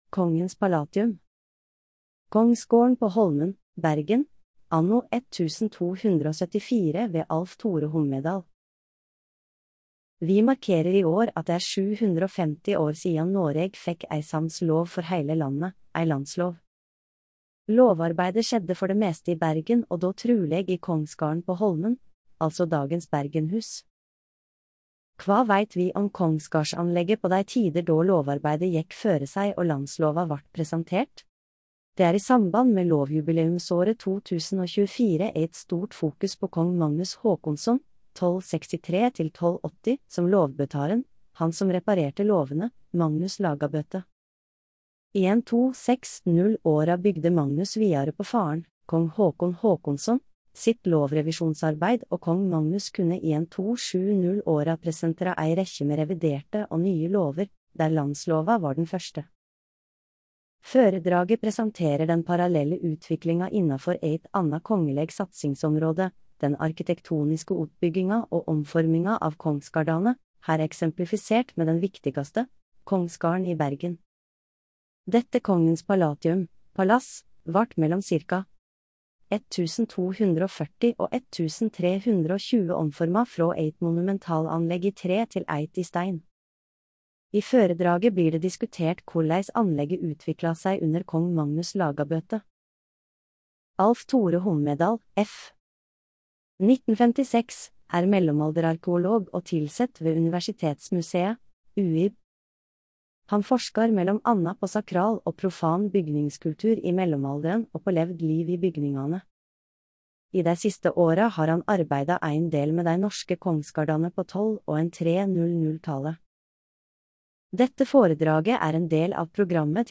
Velkommen til foredrag som er en del av programmet til Landslovjubileet i Vestland!